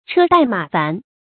車殆馬煩 注音： ㄔㄜ ㄉㄞˋ ㄇㄚˇ ㄈㄢˊ 讀音讀法： 意思解釋： 殆：通「怠」，疲乏；煩：煩躁。形容旅途勞頓。